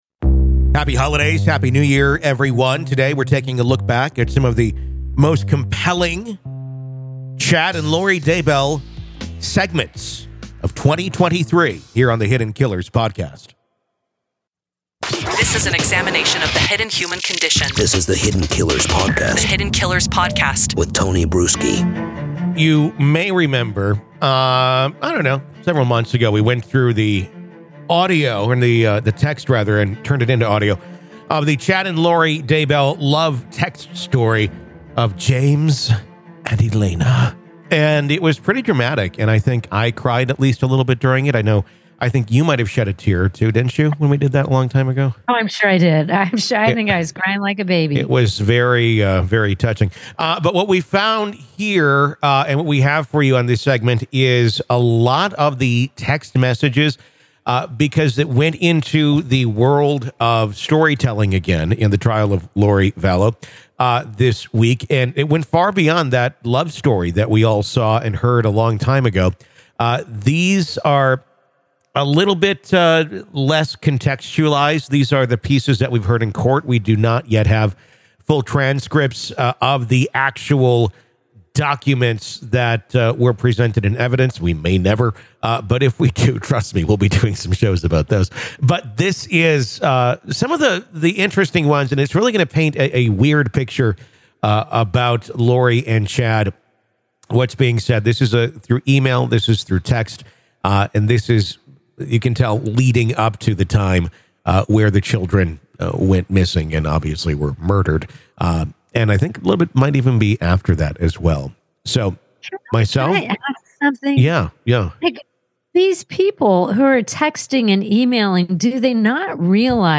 A New Dramatic Reading Of Lori & Chad Daybell's New Text Messages-Demise of the Daybells-2023 True Crime Review